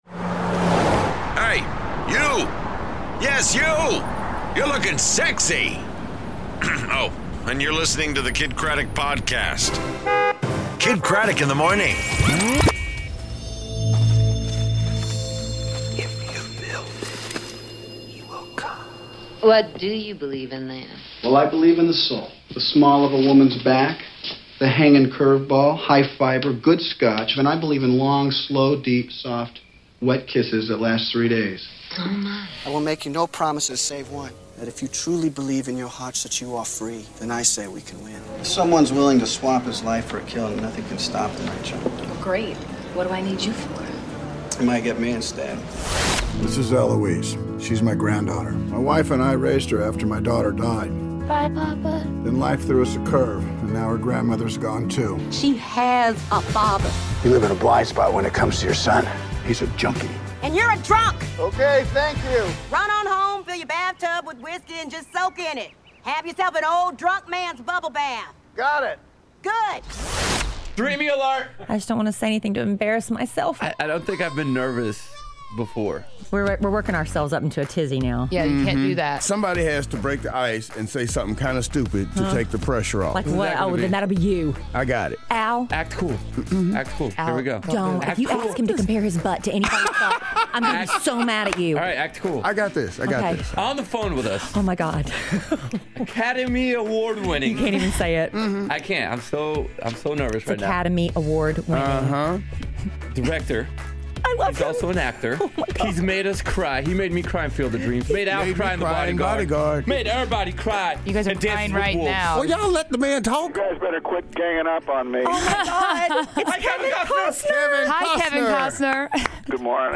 Kevin Costner On The Phone